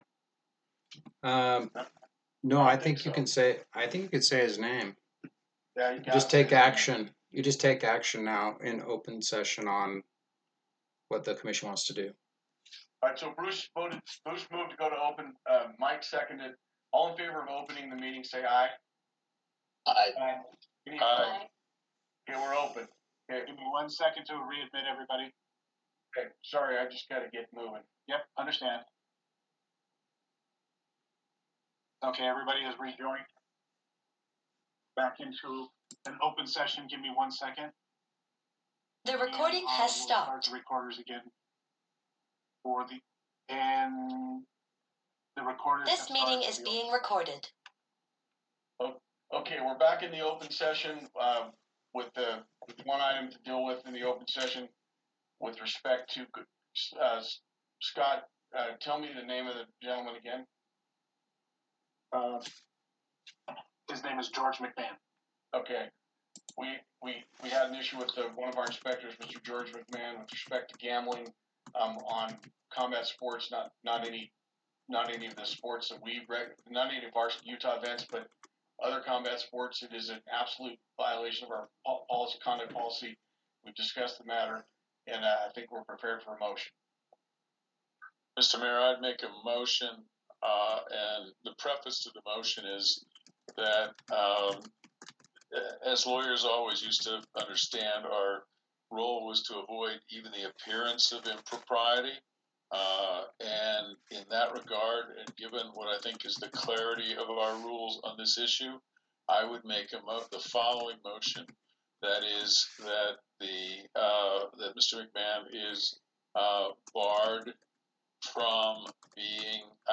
PSUAC Commission Meeting (Virtual web meeting)
Meeting